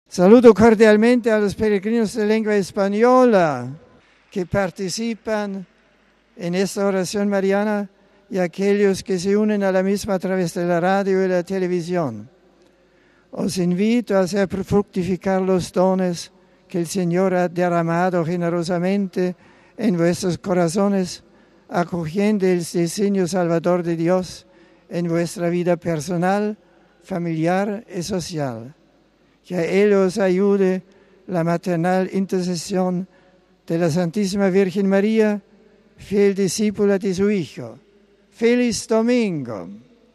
Domingo, 16 nov (RV).- Cientos de fieles se han congregado este mediodía en la plaza de San Pedro del Vaticano para unirse al Santo Padre Benedicto XVI en la oración del Ángelus de este domingo, el penúltimo del año litúrgico.
Benedicto XVI ha saludado después, como es tradicional, a todos los presentes en varios idiomas, recordando en inglés a quienes han fallecido en accidentes de tráfico, pidiendo la oración para las víctimas y sus familiares.